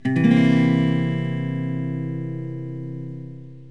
eMedia Rock Guitar Method
1 channel
C7.mp3